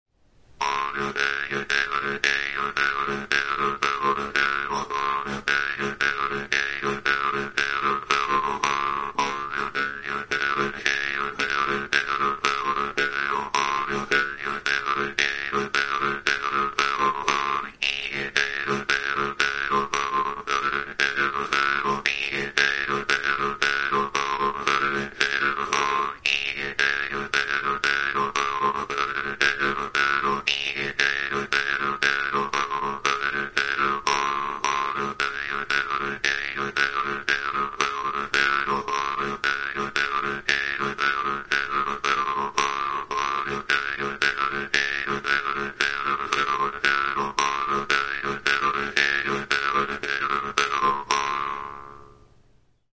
Idiófonos -> Punteados / flexible -> Sin caja de resonancia
Grabado con este instrumento.
SCACCIAPENSIERI; Tronpa; Guimbarda
Giltza forma duen metalezko uztai txabal itxia da. Altzairuzko mihi luzea du erdi-erdian, behatzarekin astintzerakoan libre bibratzen duena.